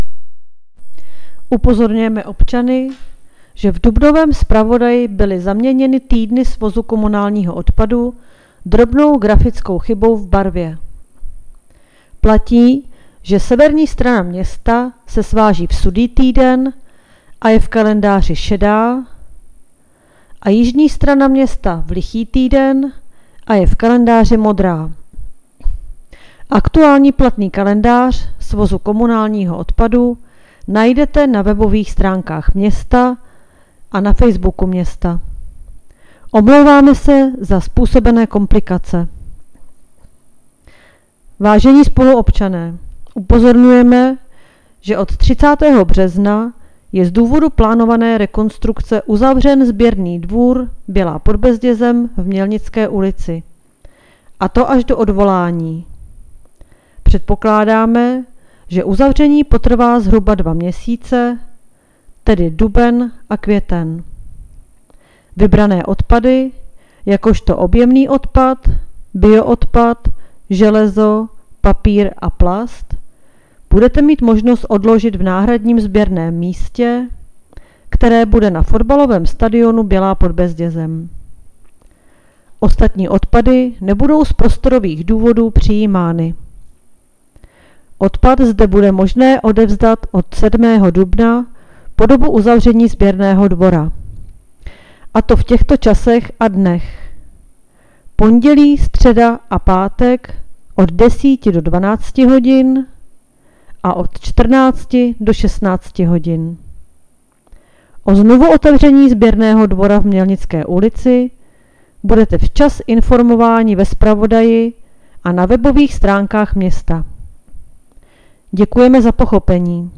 Hlášení městského rozhlasu 9.4.2021